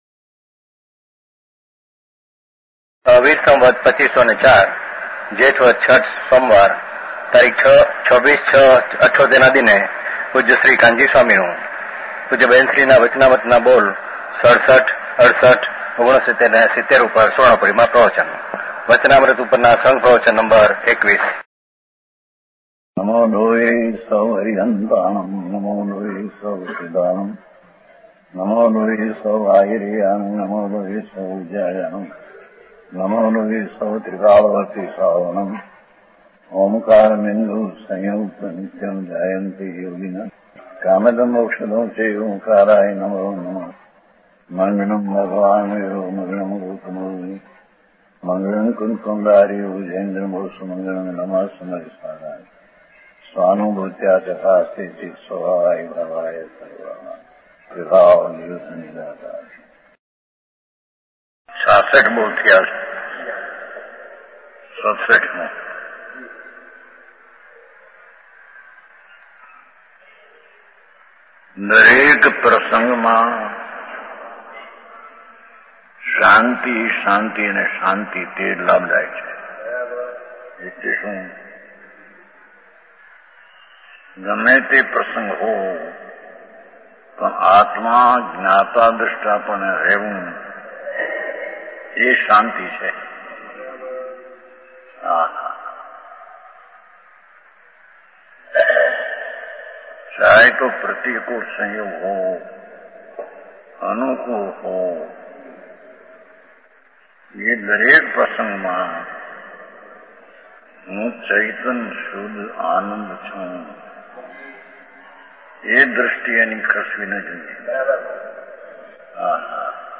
Morning Pravachan